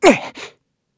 Kibera-Vox_Damage_kr_01.wav